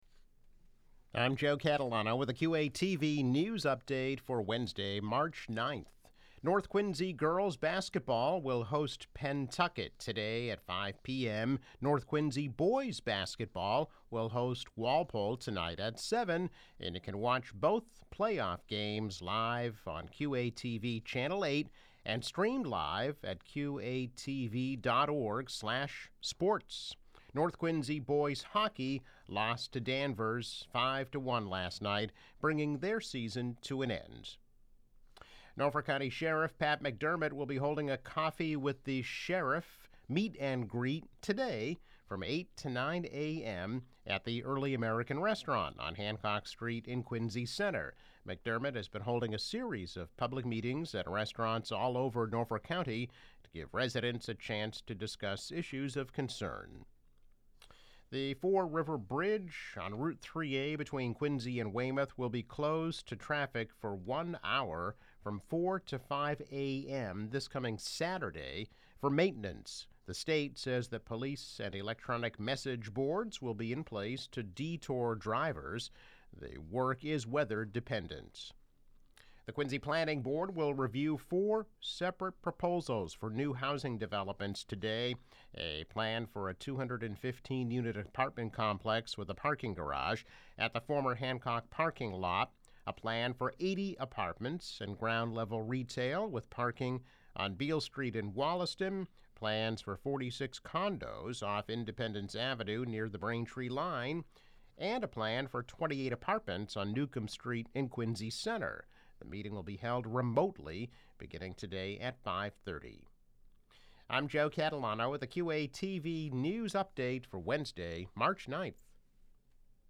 News Update - March 9, 2022